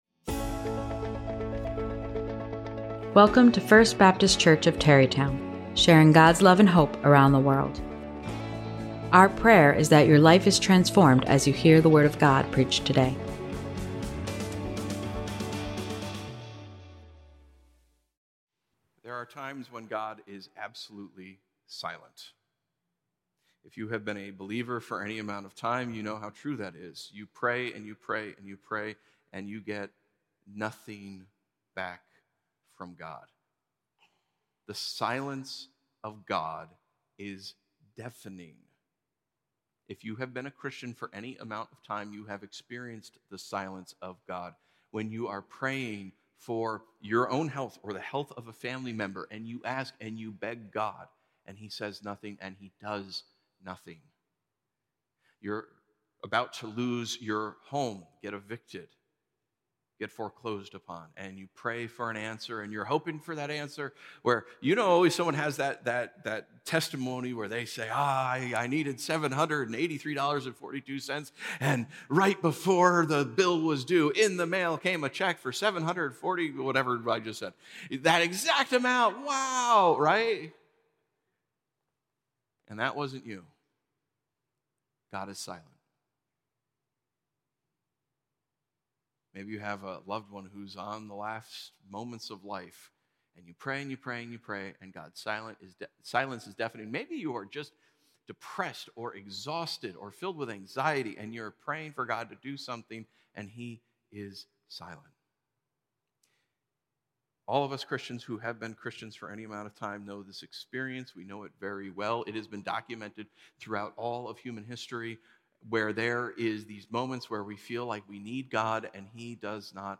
Biblical Sermons